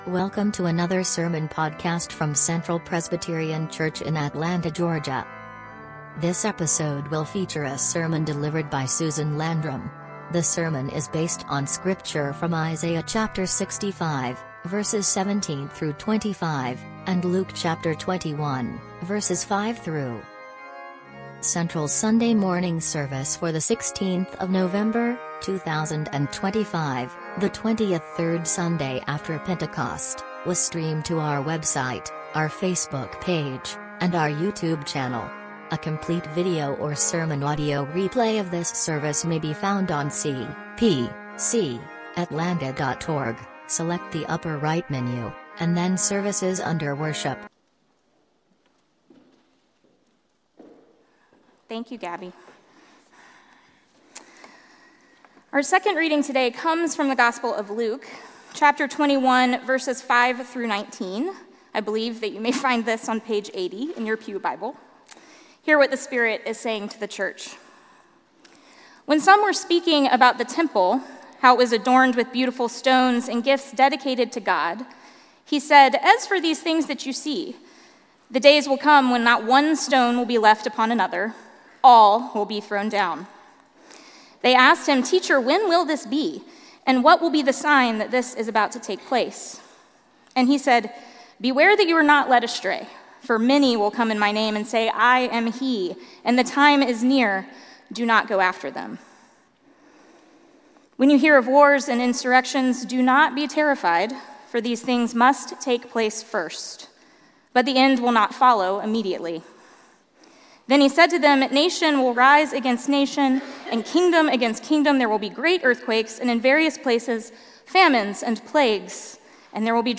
Sermon Audio:
Passage: Isaiah 65:17–25, Luke 21:5-19 Service Type: Sunday Sermon